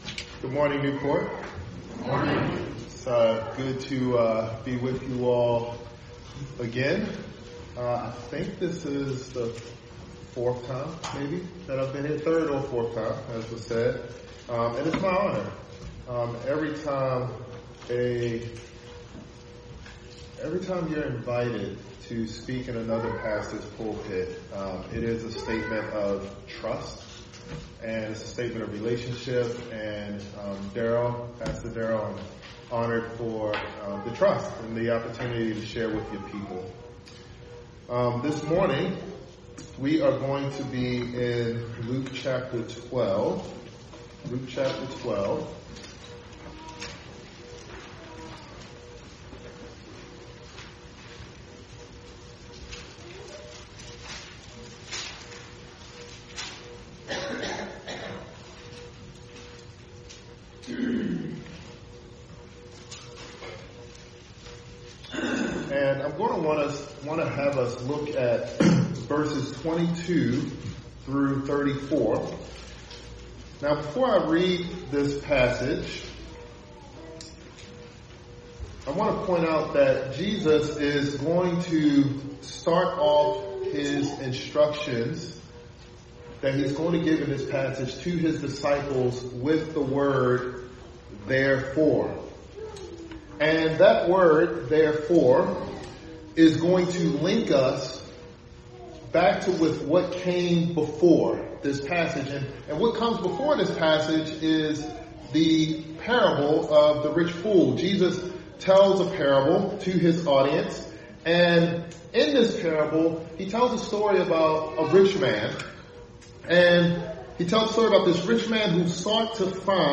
Sermon
December 14, 2025, New Port Presbyterian Church